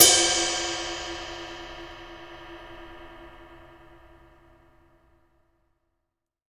BR Ride.WAV